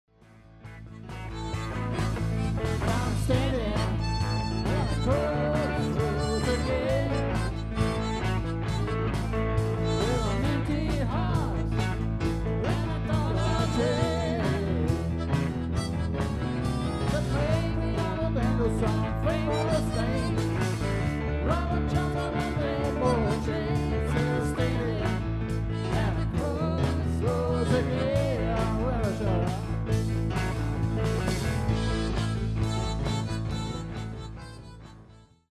Auf dieser Seite findet Ihr Hörproben unserer Auftritte.